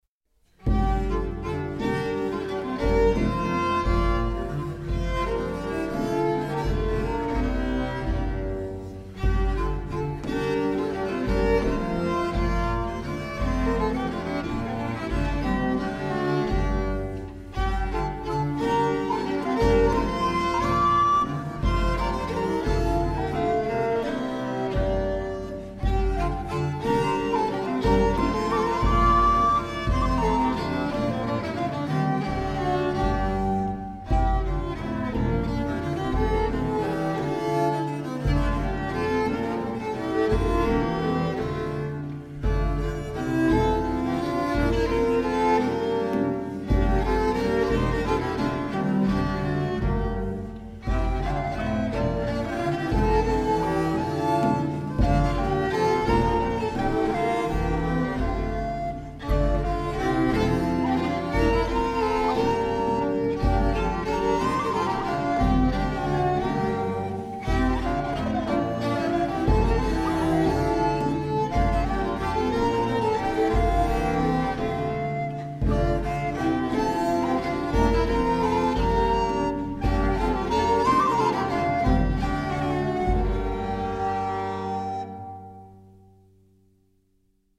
Basse danse
1320 - 1580 (Renaissance)
Group: Dance
The most popular court dance in the 15th and early 16th centuries, especially at the Burgundian court, often in a combination of 6/4 and 3/2 time allowing for use of hemiola.
In performance, 3 or 4 instrumentalists would improvise the polyphony based on this tenor.